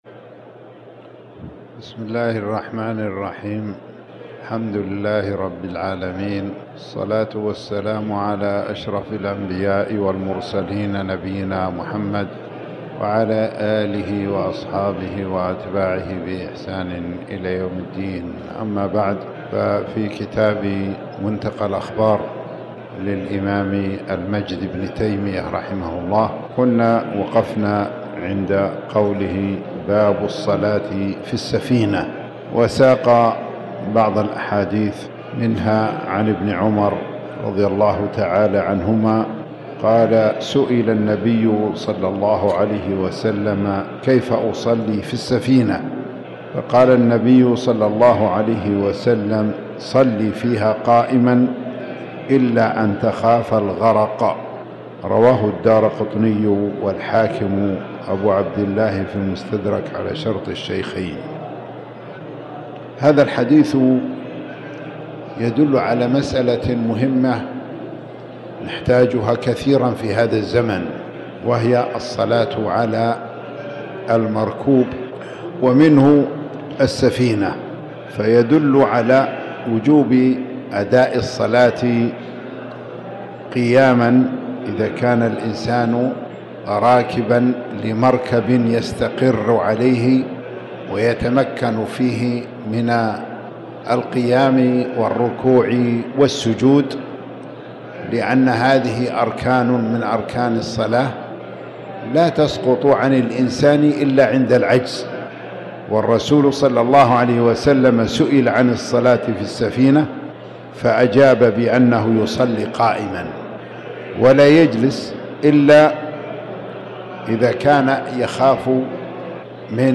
تاريخ النشر ٥ رجب ١٤٤٠ هـ المكان: المسجد الحرام الشيخ